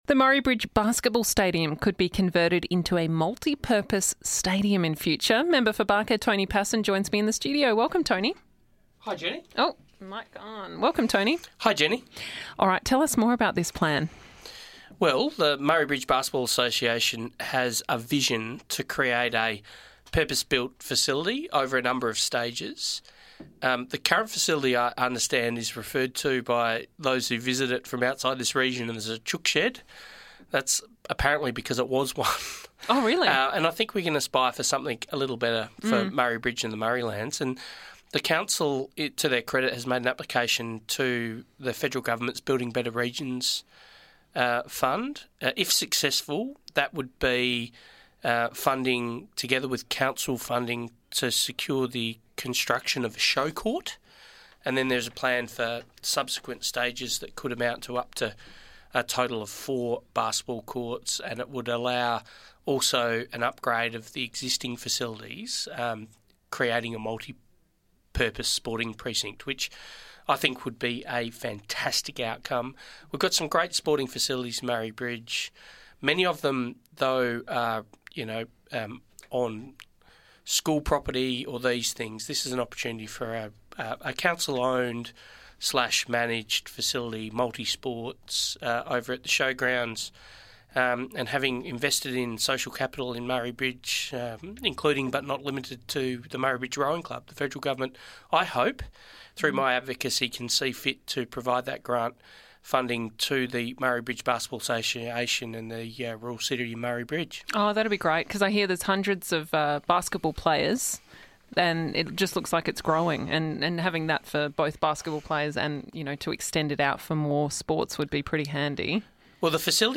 in studio to talk about the plan, discuss the latest with the Thomas Foods International rebuild and hear about new facilities at Tyndale Christian School.